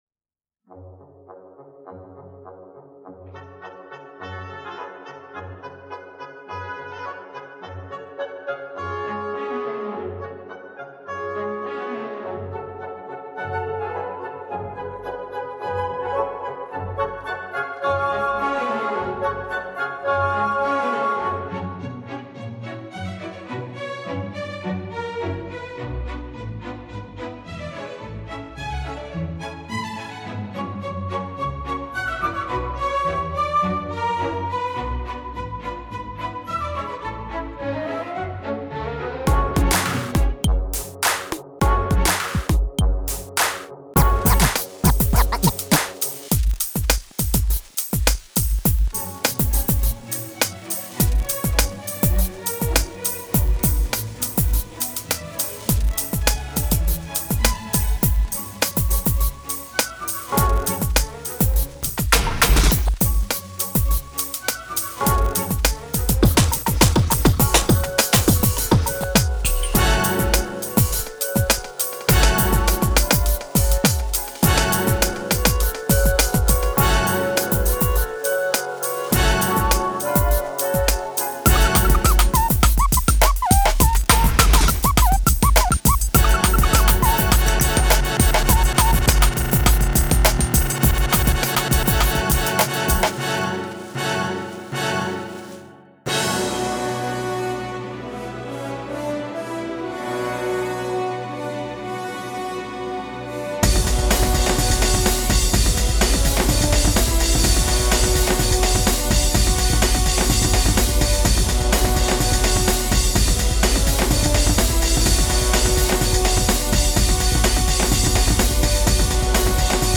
Sound design for a theater play.